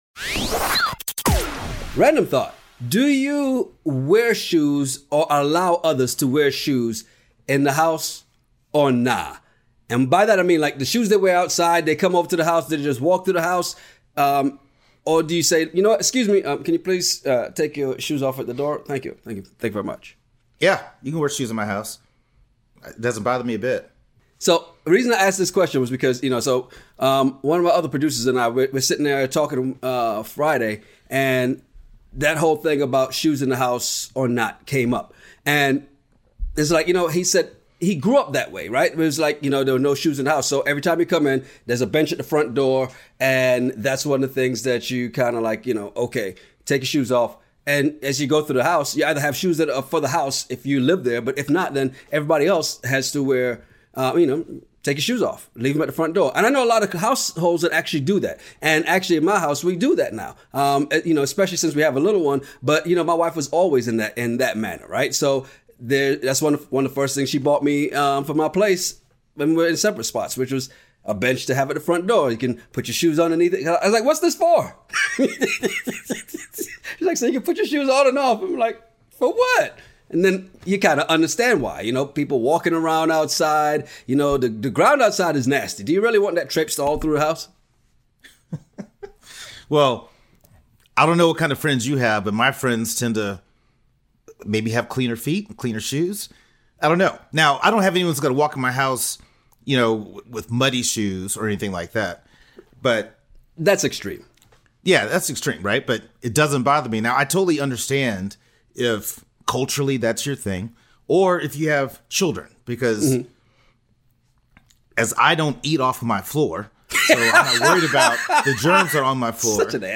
Do you make guests remove their shoes when they enter your house? Mo’Nique continues her war with DL, Ted Cruz questions Pete’s game and YK Osiris thinks women should never pay. Ever been somewhere and overheard two guys having a crazy conversation over random topics?